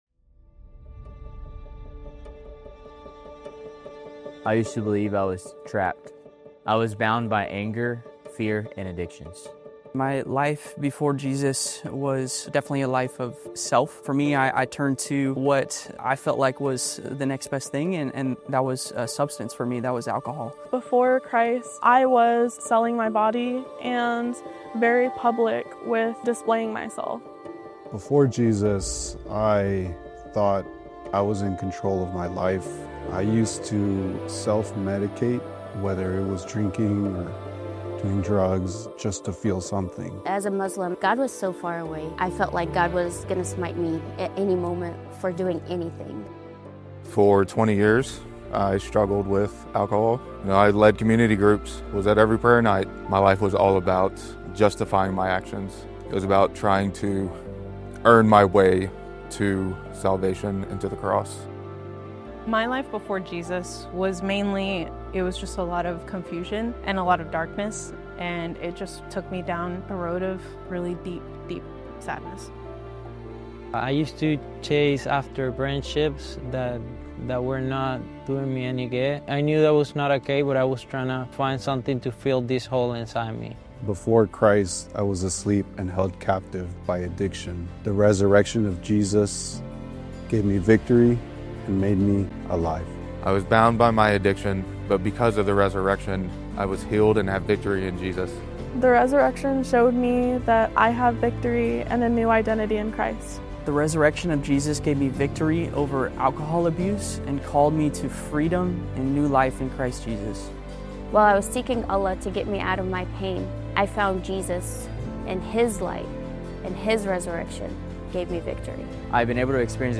Easter Sunday at Fielder Church
On Easter Sunday, we celebrated the resurrection of Jesus — the moment that changed everything. Through worship and the preaching of God’s Word, we were reminded that because Jesus rose from the grave, hope is alive, sin is defeated, and new life is possible for all who believe.